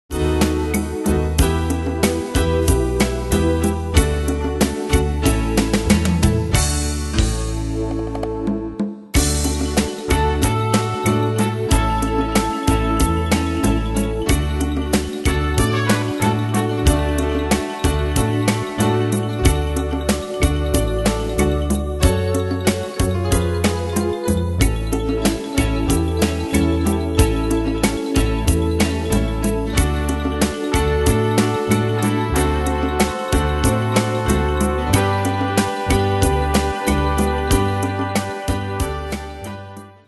Style: PopAnglo Ane/Year: 1998 Tempo: 93 Durée/Time: 3.18
Danse/Dance: Rhumba Cat Id.
Pro Backing Tracks